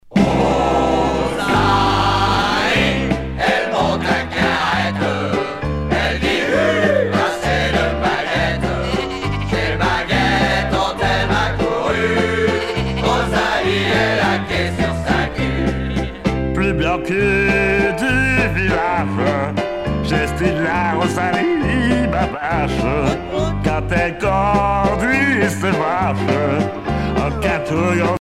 valse musette
Genre strophique